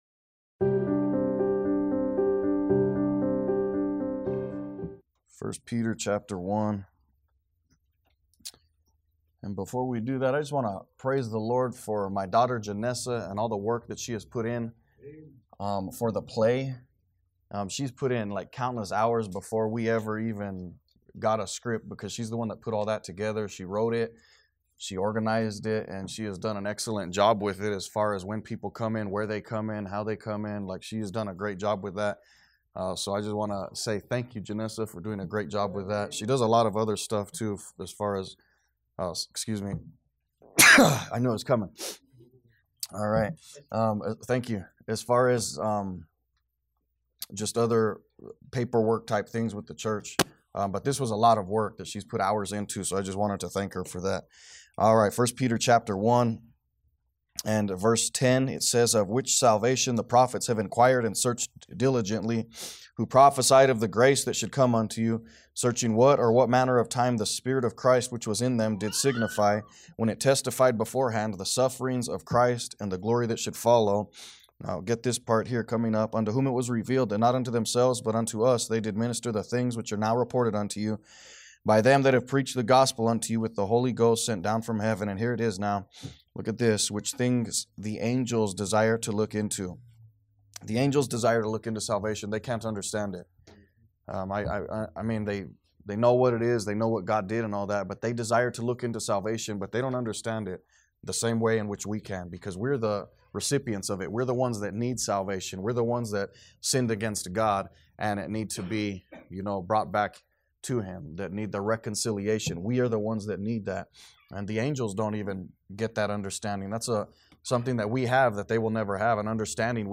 Sermons | Liberty Baptist Church